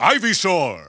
The announcer saying Ivysaur's name in English releases of Super Smash Bros. Brawl.
Ivysaur_English_Announcer_SSBB.wav